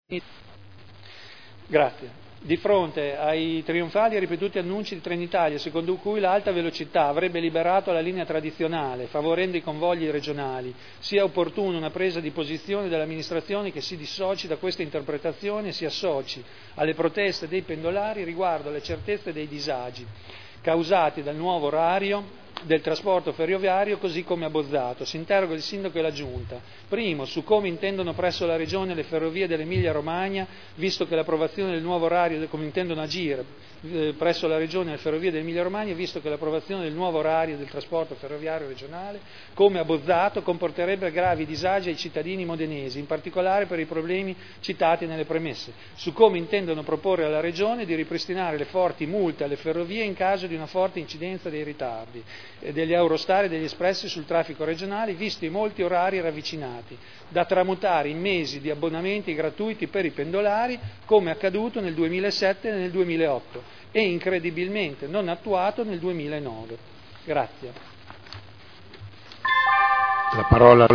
Seduta del 14/12/2009. nuovo orario del trasporto ferroviario e sulla tutela dei diritti dei cittadini pendolari